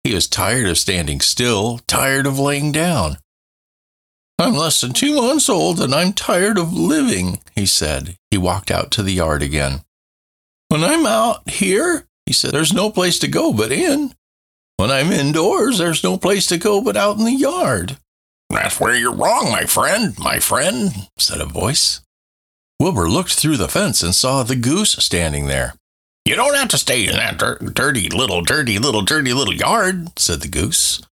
Childrens Storyteller
Narration_Childrens_Storyteller.mp3